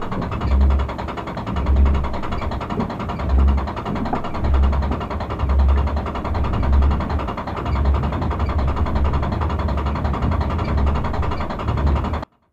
Деревянный механизм